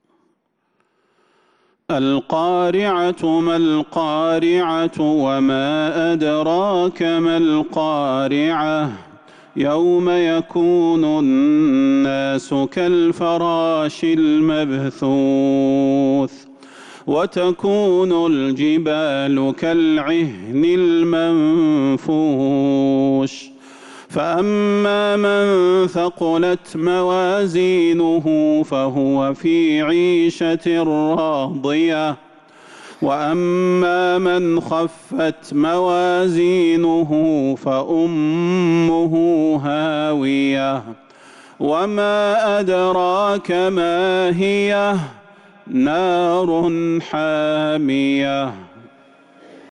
سورة القارعة | رجب 1447هـ > السور المكتملة للشيخ صلاح البدير من الحرم النبوي 🕌 > السور المكتملة 🕌 > المزيد - تلاوات الحرمين